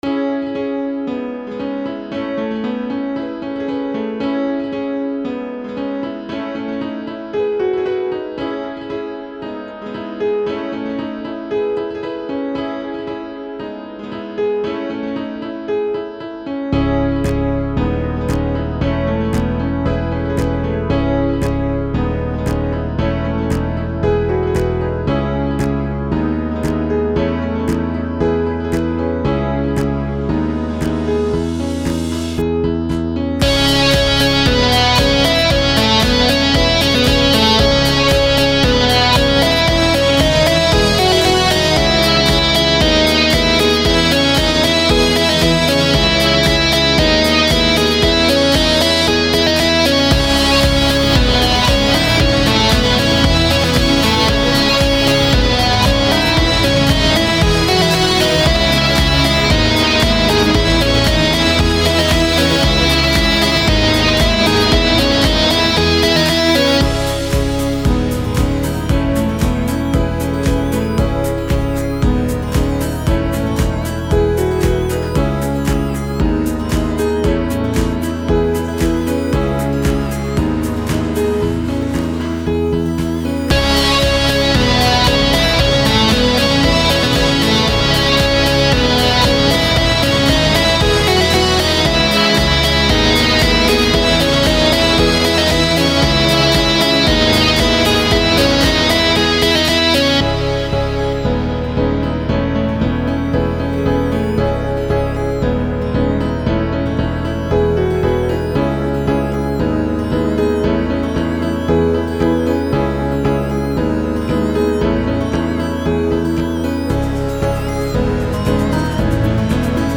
קטע אינסטרומנטלי חדש!!!
נ.ב. לא עבר מיקס עד הסוף ולא מאסטרינג.